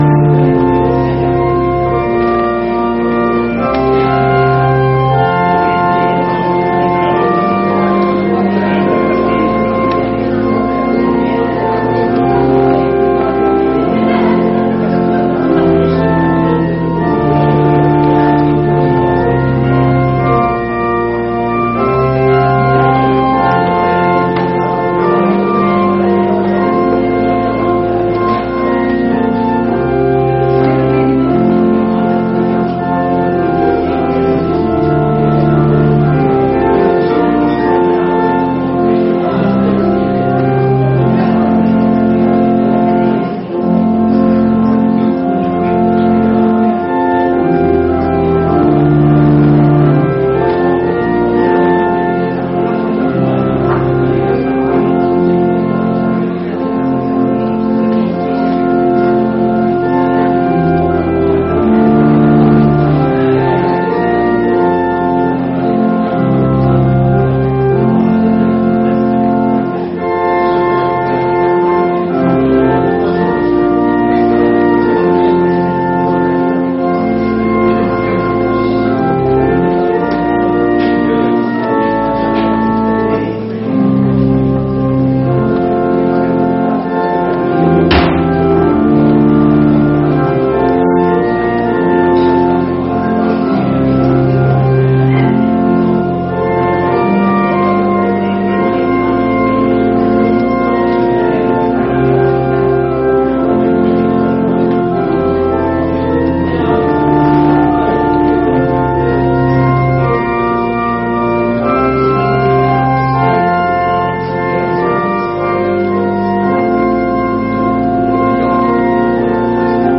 Gottesdienst Ostermontag 06.04.2026 | Evangelisch-altreformierte Kirchengemeinde Laar
Wir laden ein, folgende Lieder aus dem Evangelischen Gesangbuch und dem Liederheft mitzusingen: Lied 177, 2, Lied 106, 1 – 5, Lied 115, 1 – 4, Psalm 118, 1 – 3, Psalm 118, 5 + 9 + 10, Lied 170, 1 – 4